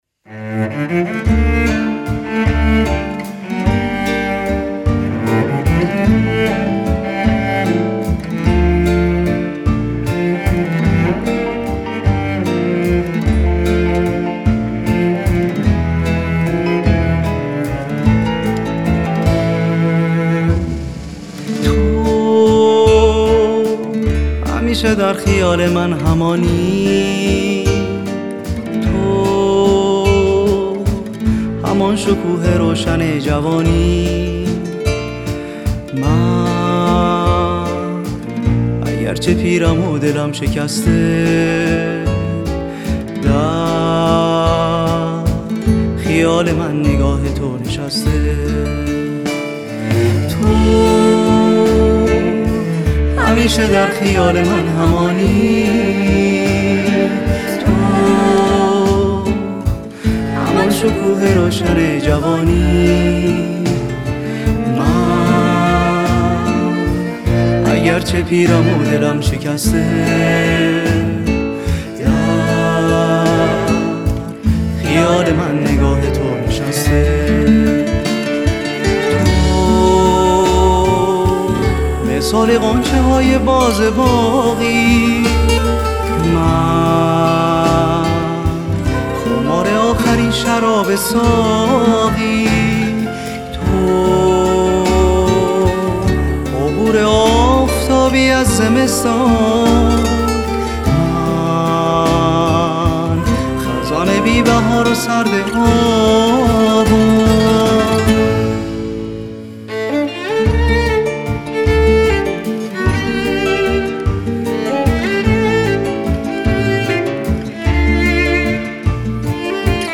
⚪ویولن و چلو
⚪ژانر: پاپ